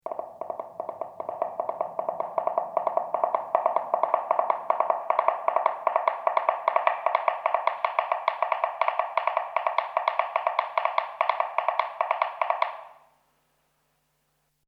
Galope de caballo
caballo
Sonidos: Animales